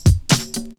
38DR.BREAK.wav